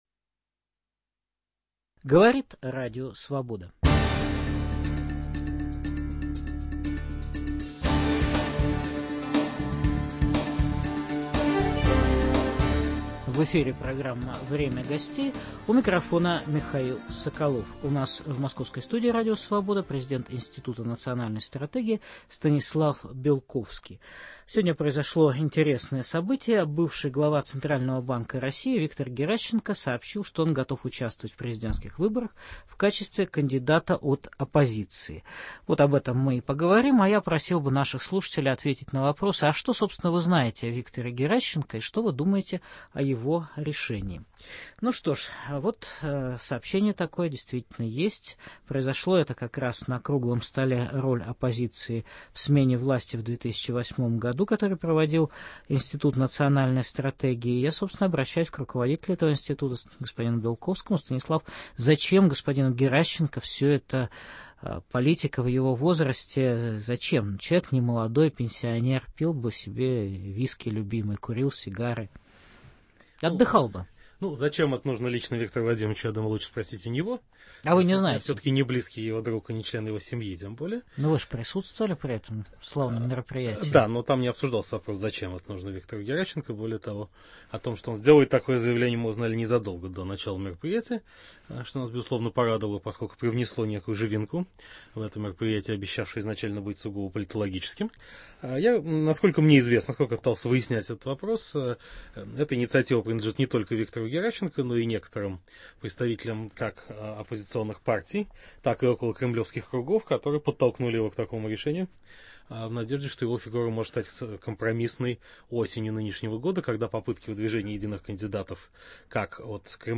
О демографической революции, о природе кризиса развития человечества, об ускорении исторического времени - в беседе с вице-президентом РАЕН, президентом Евразийского физического общества, членом Римского клуба, профессором Сергеем Капицей.